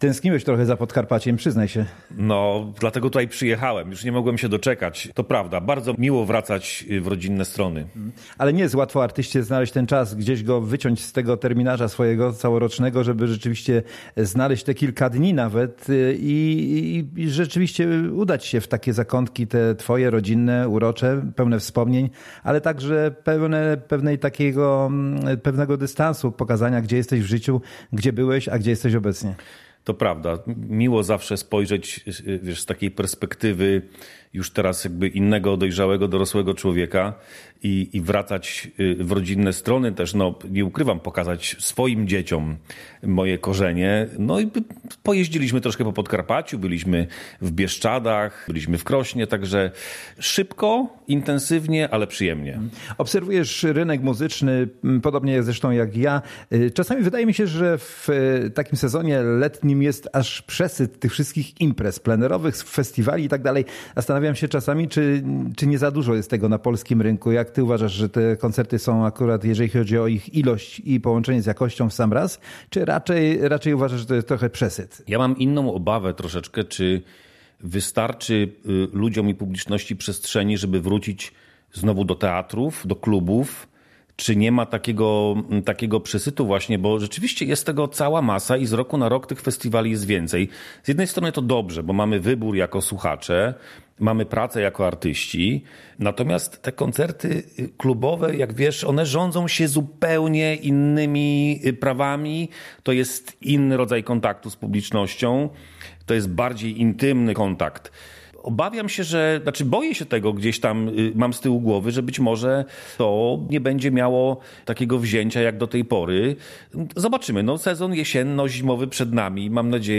Wizyta ta była doskonałą okazją, by spotkać się i porozmawiać o muzyce i nie tylko.